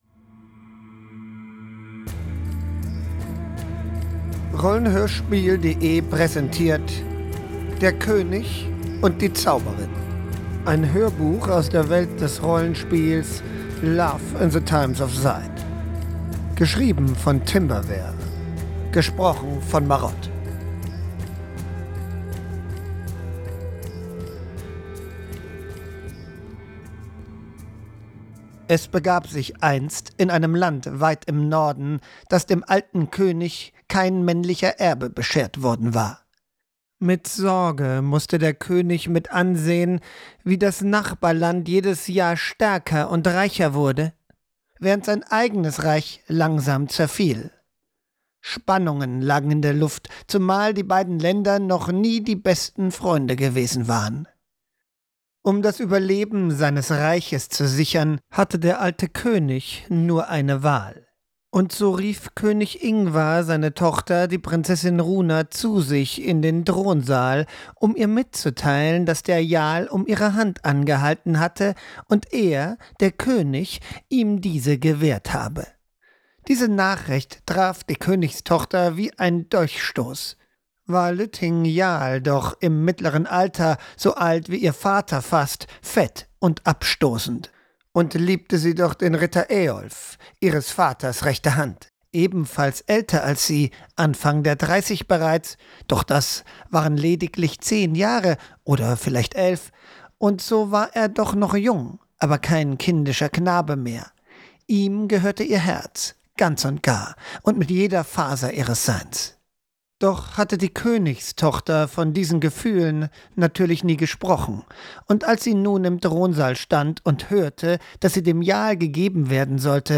der-koenig-und-die-zauberin-wikinger-fantasy-hoerbuch.mp3